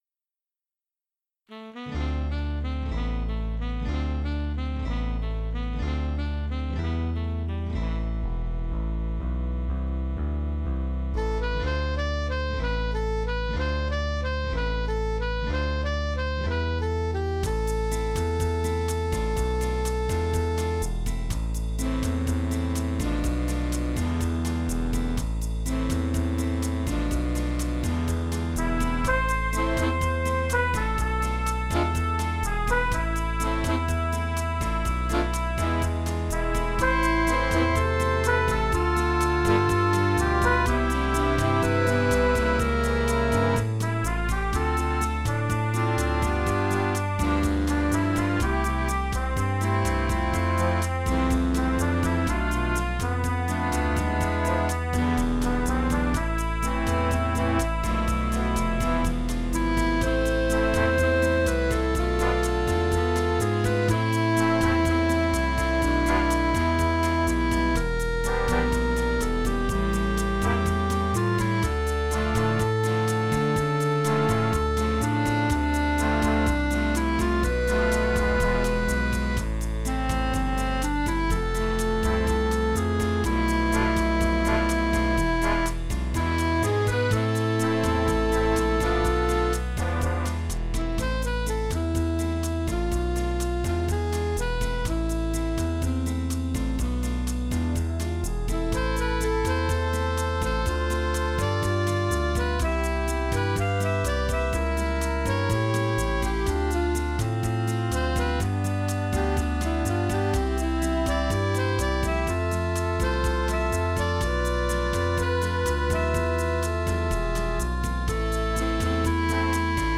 Partitions et enregistrements audio séquenceur du morceau Manha de Carnaval, de Louis Bonfi, Jazz.
Genre: Jazz
Orchestre d'Harmonie